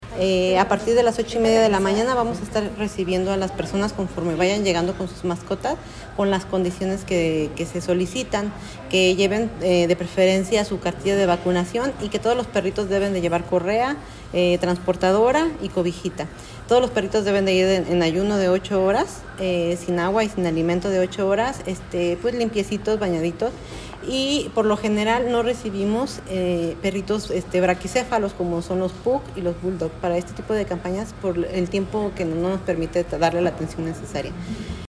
AudioBoletines